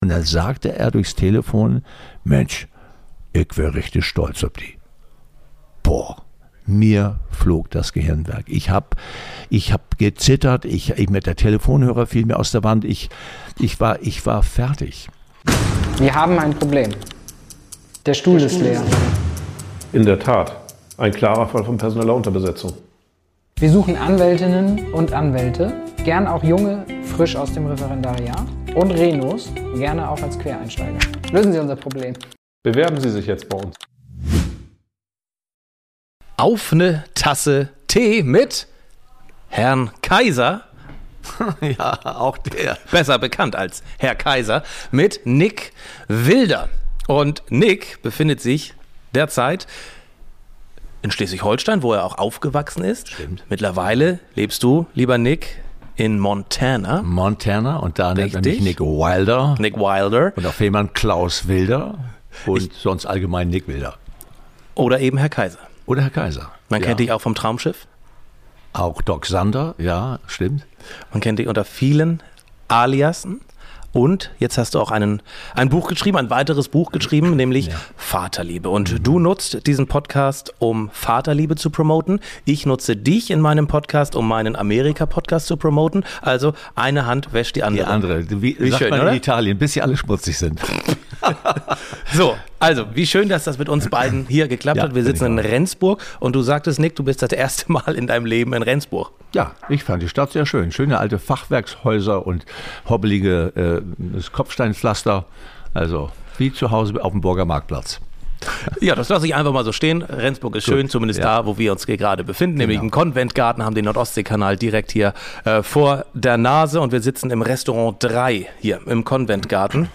Entstanden ist im ConventGarten in Rendsburg ein intensives Gespräch über Kindheit, Prägung, Schmerz, Verständnis und die Frage, ob man im Rückblick Frieden mit dem finden kann, was gefehlt hat.